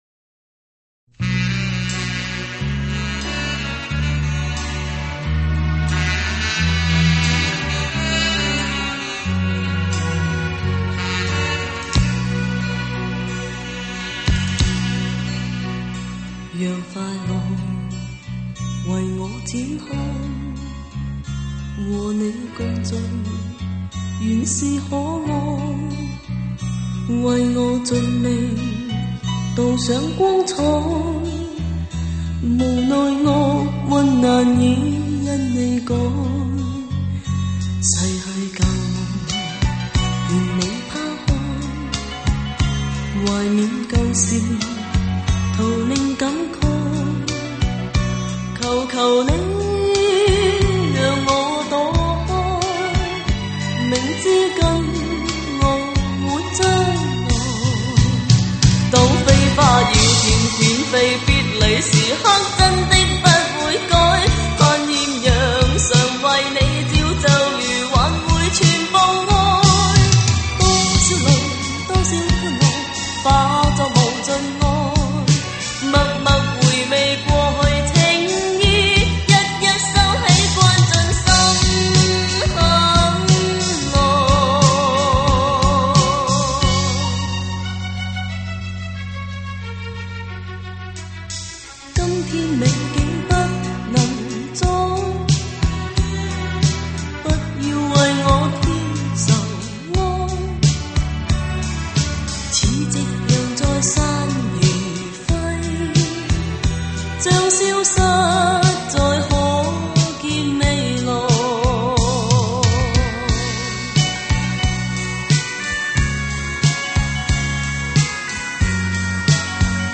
粤语经典